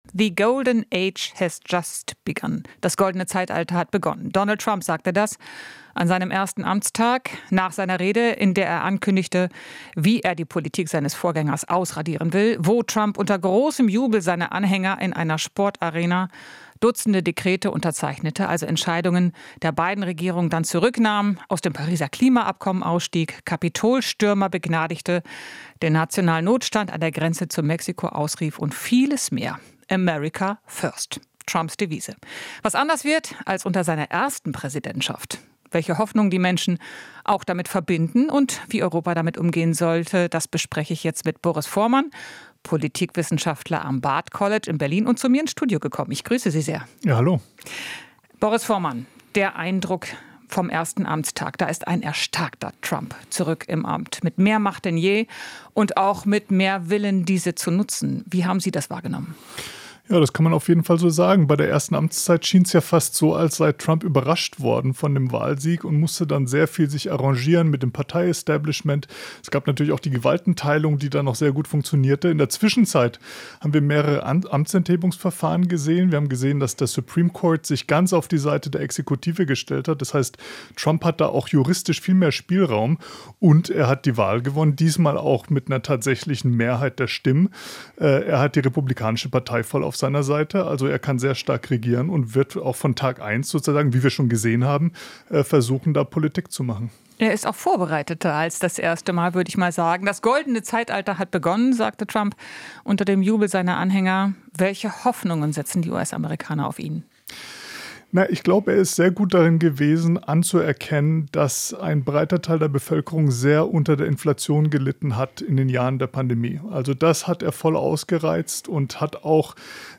In Interviews, Beiträgen und Reportagen bilden wir ab, was in der Welt passiert, fragen nach den Hintergründen und suchen nach dem Warum.